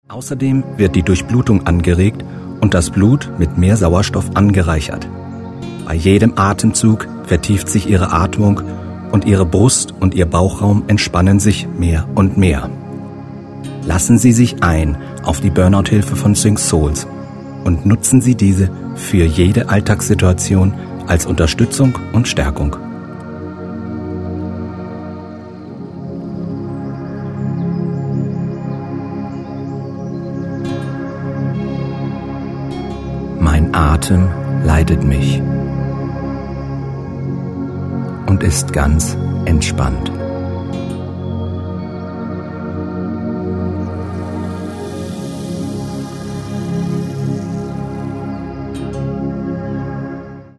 Männliche Stimme   15:30 min